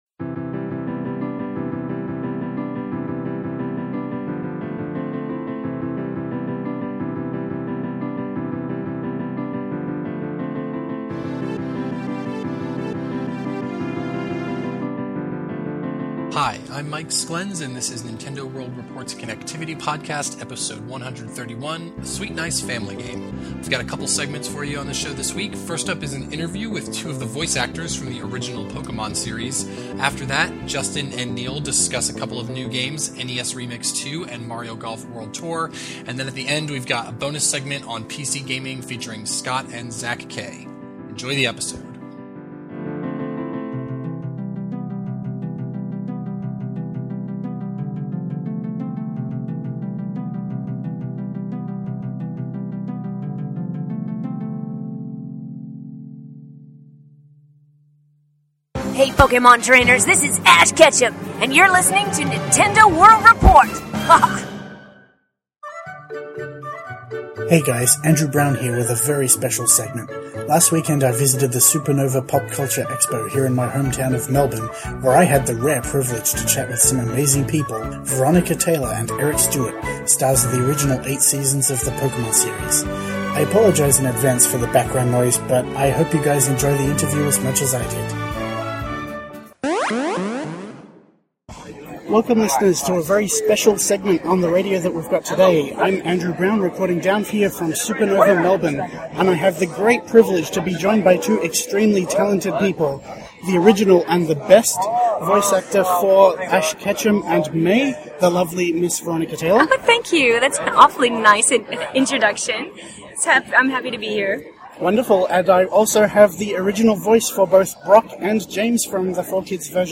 After that we have a real special treat for you: an interview with two of the voice actors from the original Pokémon cartoon.